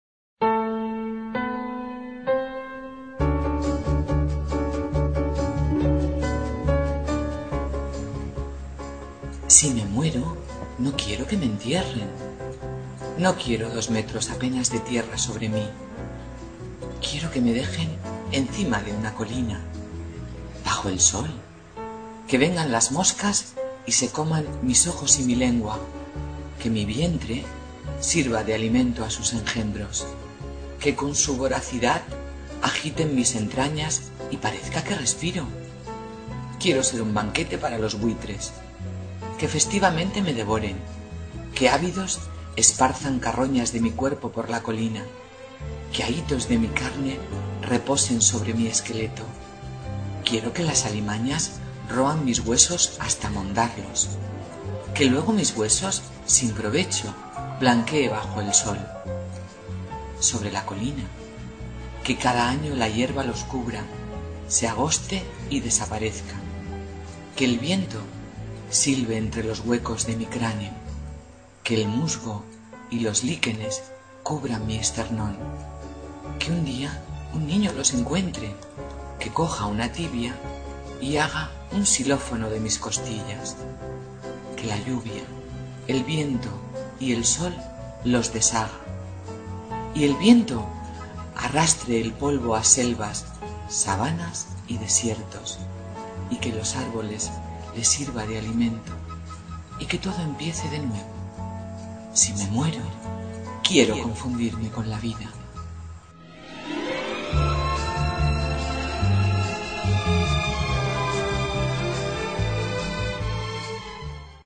Inicio Multimedia Audiopoemas Entretanto.